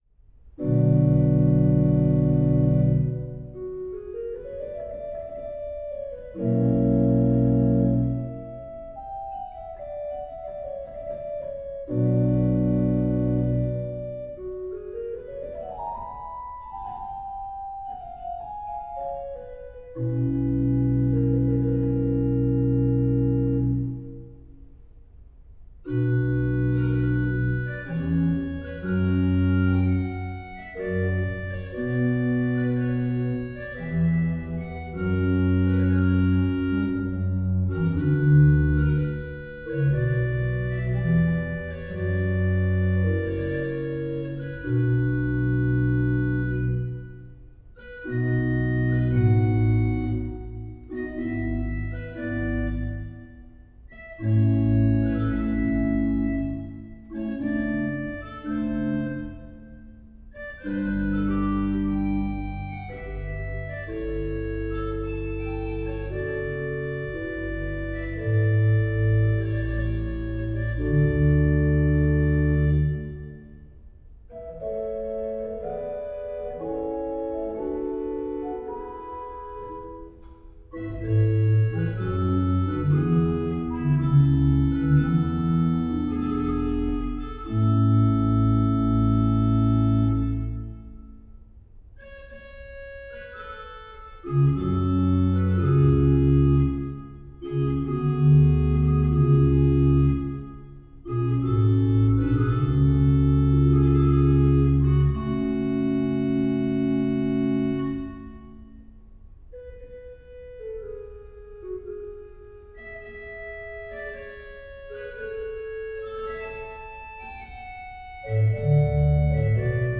Organ of St. Pankratius-Kirche Ochsenwerder
Largo
Orgel